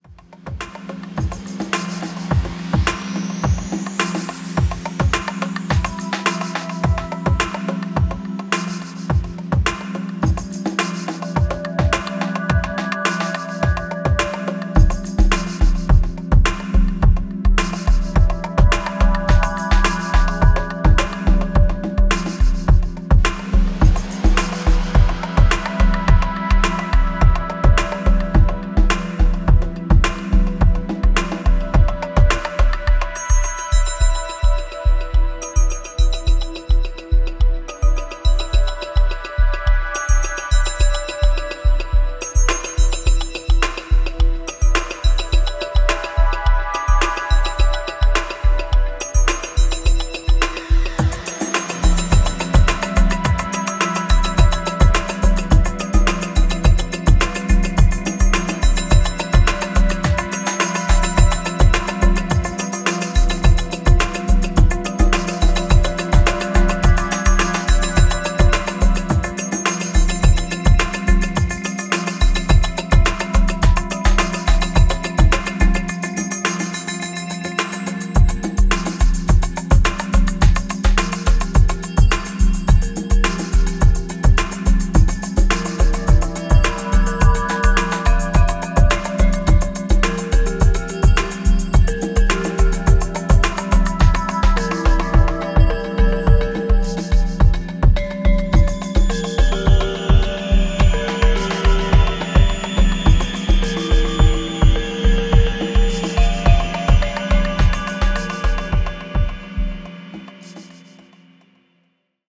105BPMのアンビエントなレフトフィールド・ダンスホール